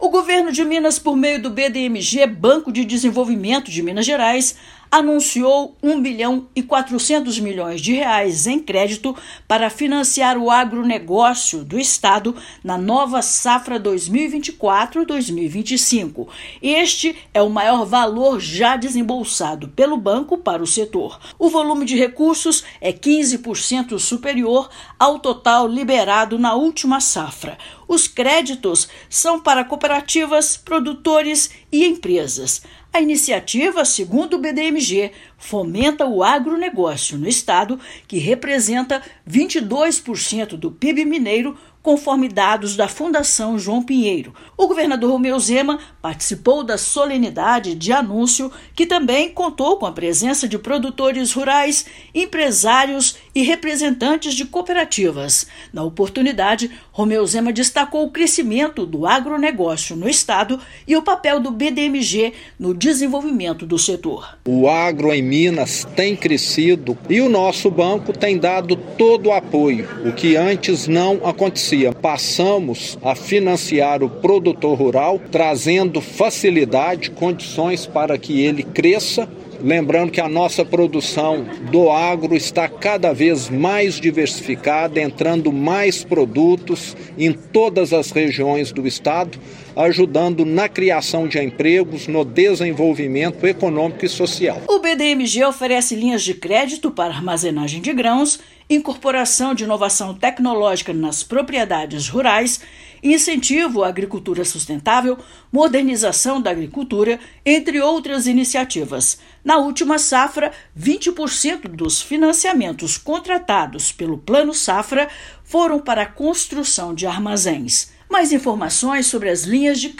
Financiamento para a nova Safra 2024/2025 é o maior volume de recursos já direcionado pelo banco ao setor. Ouça matéria de rádio.